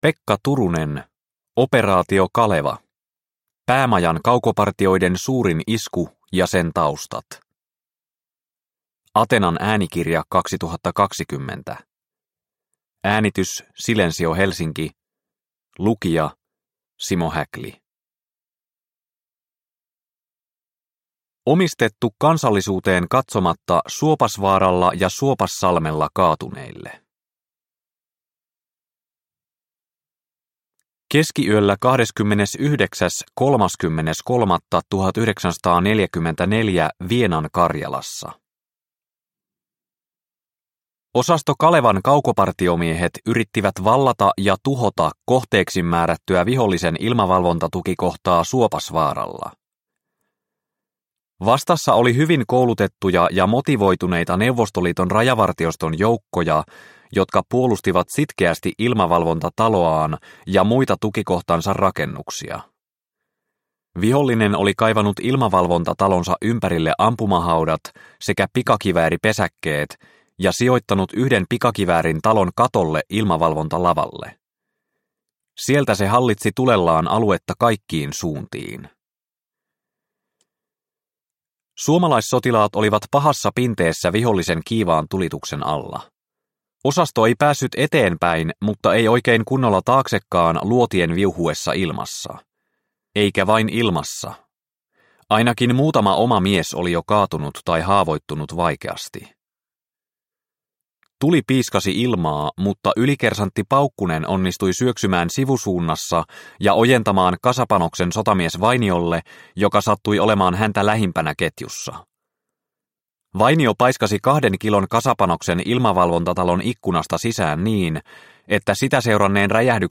Operaatio Kaleva – Ljudbok – Laddas ner